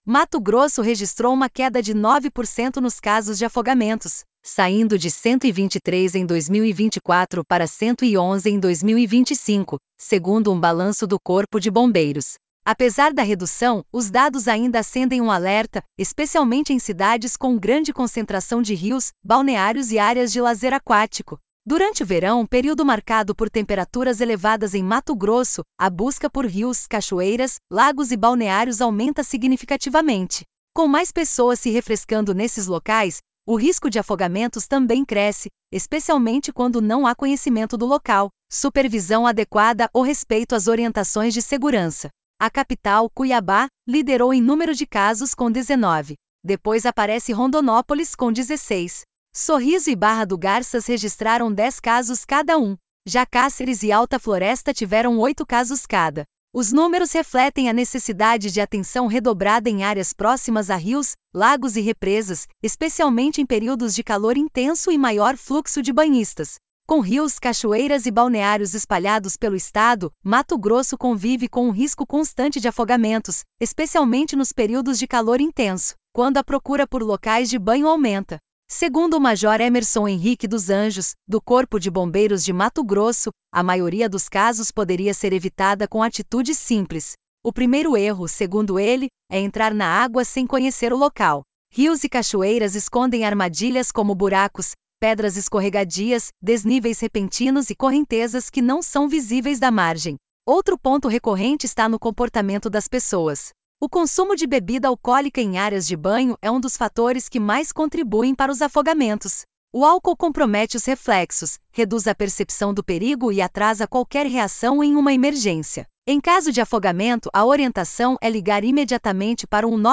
Boletins de MT 05 jan, 2026